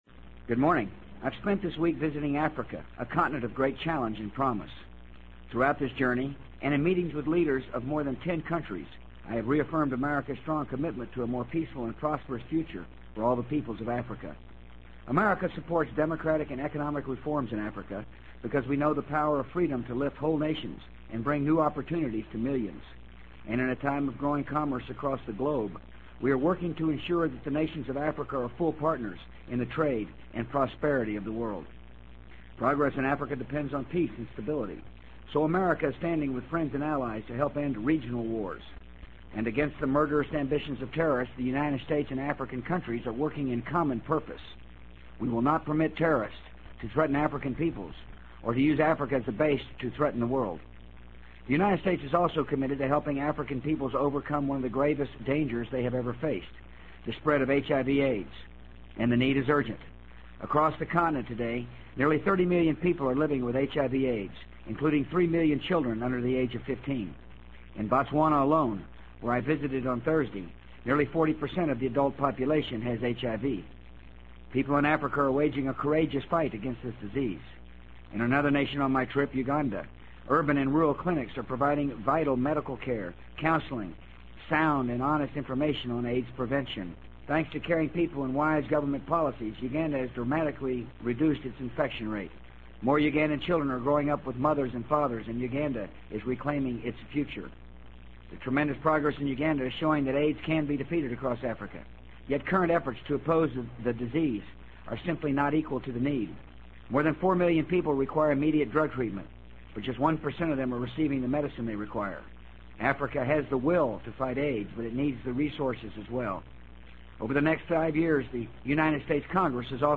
【美国总统George W. Bush电台演讲】2003-07-12 听力文件下载—在线英语听力室